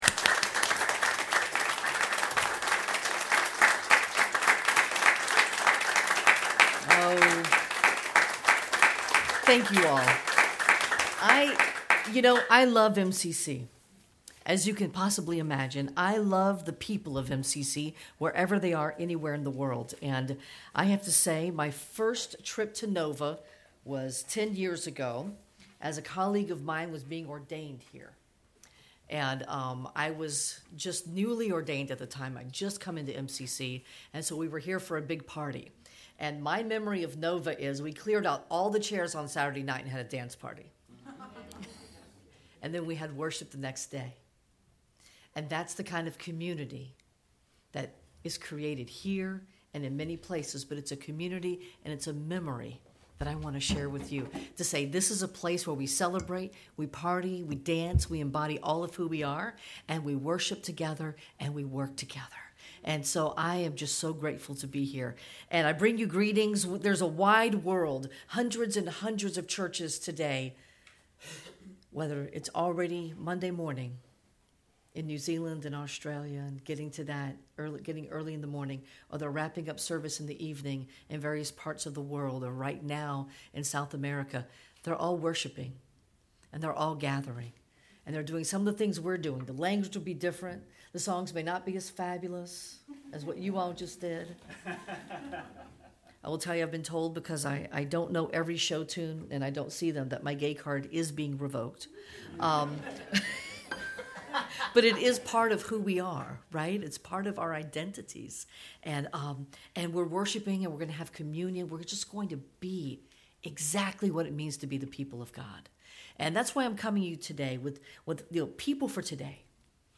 10/22 Sermon Posted